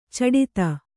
♪ caḍita